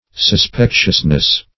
Search Result for " suspectiousness" : The Collaborative International Dictionary of English v.0.48: Suspectiousness \Sus*pec"tious*ness\, n. Suspiciousness; cause for suspicion.